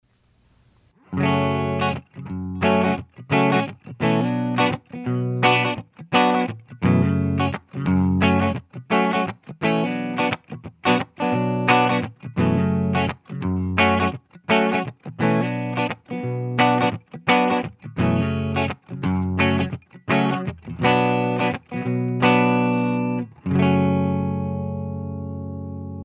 • Falante de Guitarra
Purple Hemp é o 2° falante com cone composto com fibra de cânhamo fabricado no Brasil,possui um low-end encorpado juntamente ao seus graves firmes, médios equilibrados e médios agudos suaves, tornando um falante equilibrado e sem picos evidentes.
CLEAN
Purple-Hemp-Clean.mp3